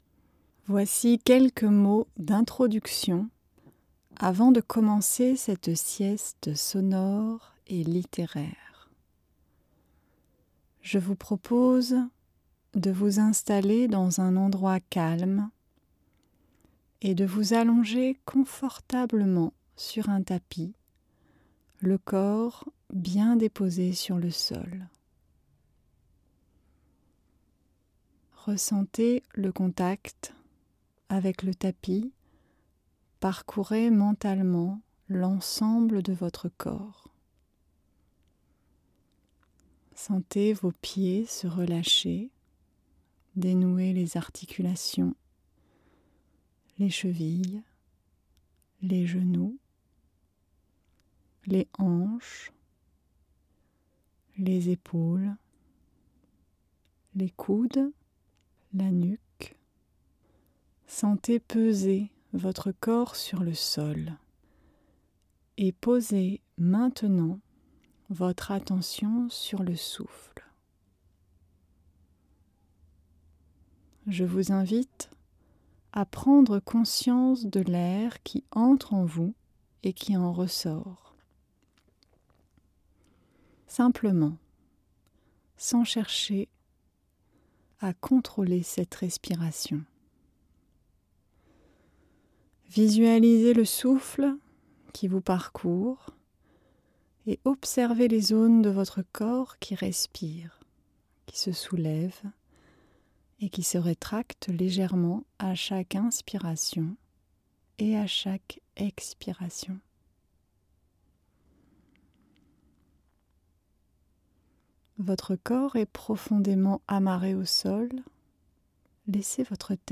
Installé dans un endroit calme, prenez le temps de vous allonger confortablement pour une sieste méditative.